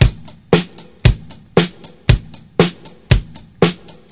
116 Bpm Drum Groove A# Key.wav
Free drum loop - kick tuned to the A# note. Loudest frequency: 4407Hz
116-bpm-drum-groove-a-sharp-key-pPn.ogg